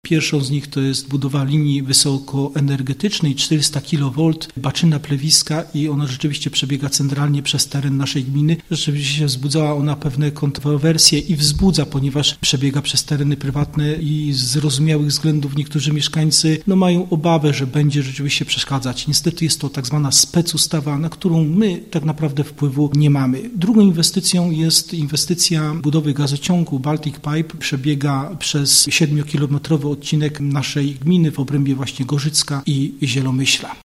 ’- Przez nasza gminę pobiegnie nowy gazociąg i linia energetyczna – tłumaczy Józef Piotrowski, wójt gminy Pszczew.